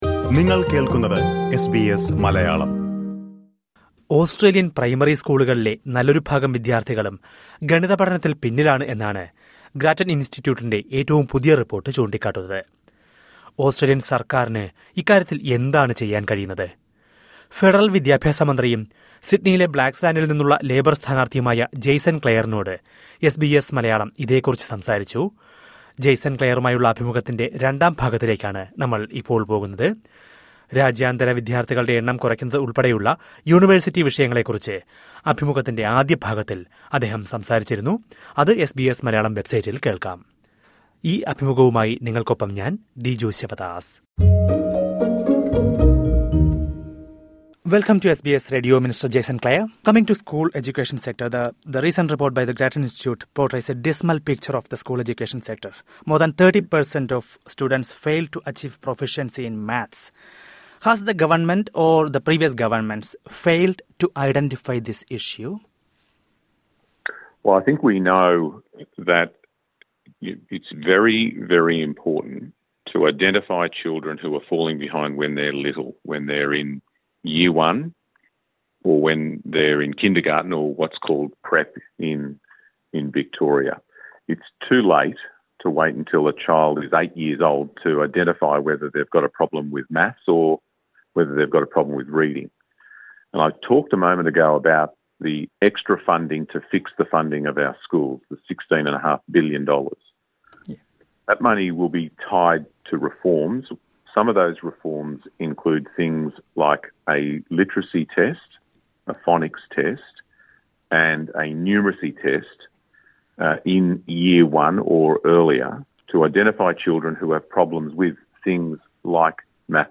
ഫെഡറല്‍ വിദ്യാഭ്യാസമന്ത്രിയും, ലേബര്‍ സ്ഥാനാര്‍ത്ഥിയുമായ ജേസന്‍ ക്ലെയറിനോട് എസ് ബി എസ് മലയാളം സംസാരിക്കുന്നത് കേള്‍ക്കാം...